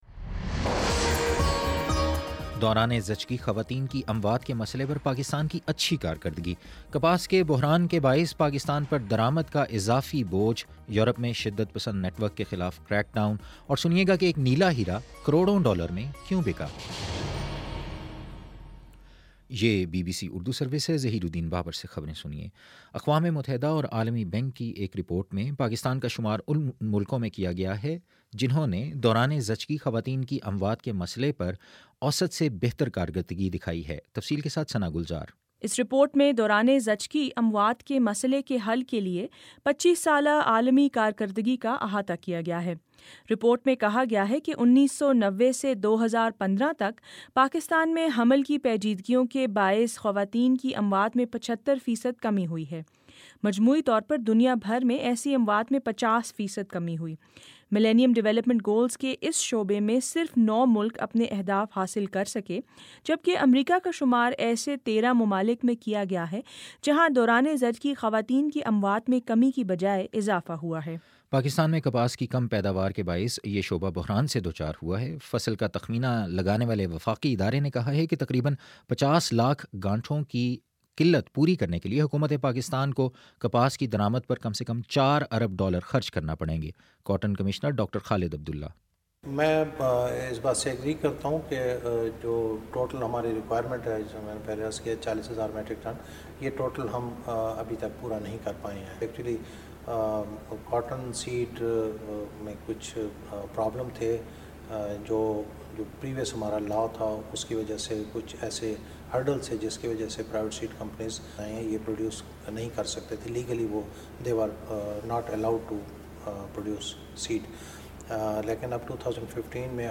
نومبر 12: شام سات بجے کا نیوز بُلیٹن